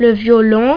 violin.mp3